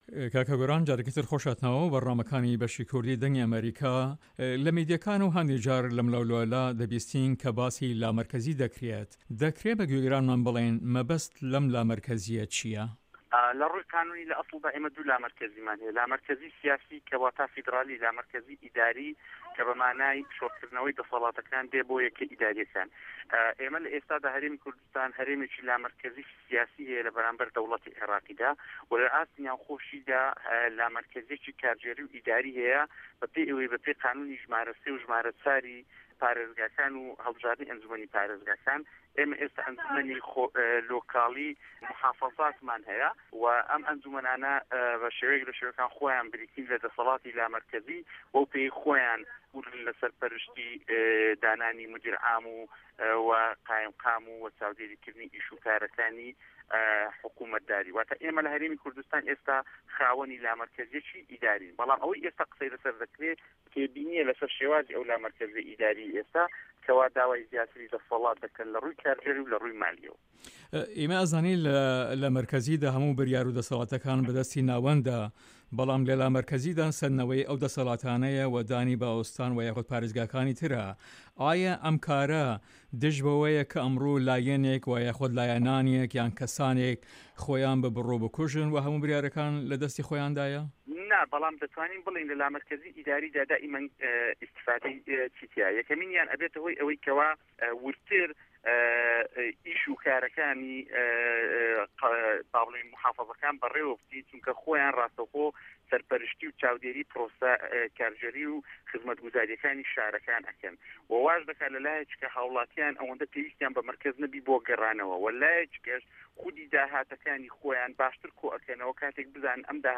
گۆران ئازاد ئەندام پەرلەمانی هەرێمی کوردستان لە هەڤپەیڤینێکدا لەگەڵ بەشی کوردی دەنگی ئەمەریکا دەڵێت" لە رووێ یاساییەوە ئێمە دوو لامەرکەزیمان هەیە، لامەرکەزی سیاسی کە واتە فیدراڵی، لامەرکەزی ئیداری ( بەرێوەبەرایەتی) کە بە واتاێ شۆرکردنەوەی دەسەڵاتەکان بۆ یەکە ئیداریەکان.